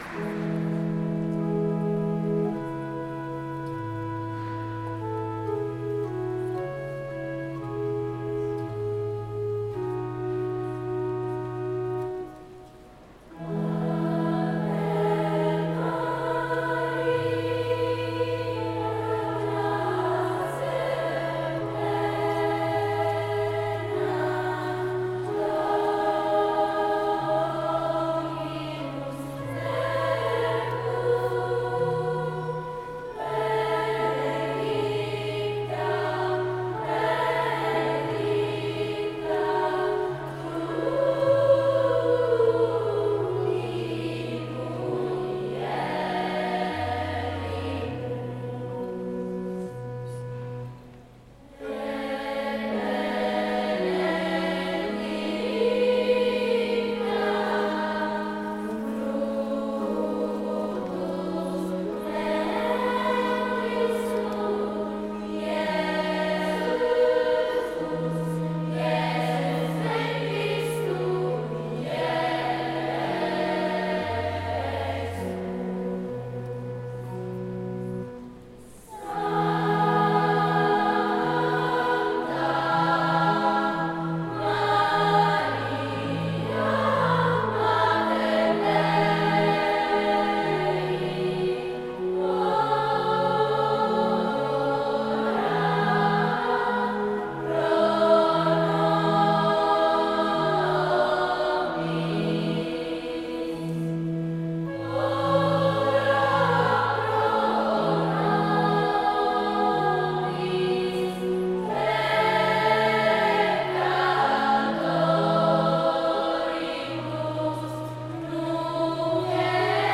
Gallery >> Audio >> Audio2017 >> Rassegna Corali Diocesane >> 02-RassCorali 26Nov2017 PueriBalestrate